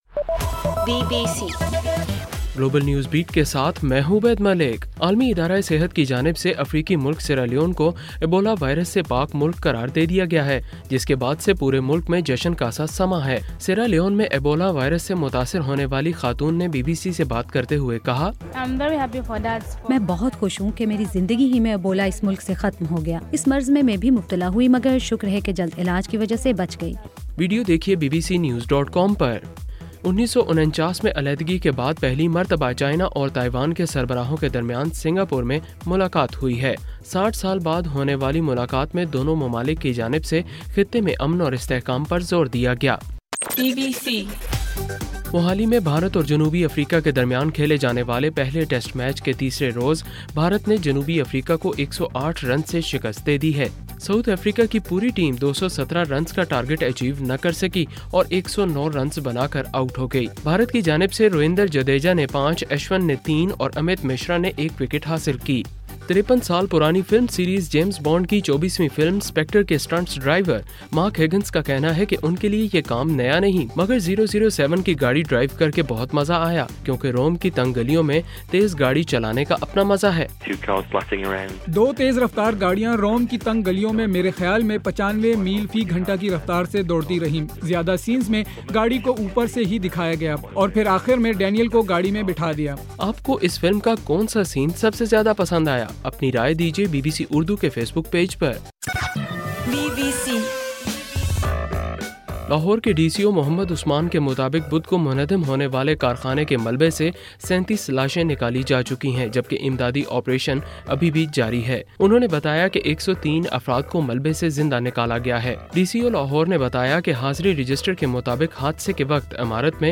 نومبر 7: رات 9 بجے کا گلوبل نیوز بیٹ بُلیٹن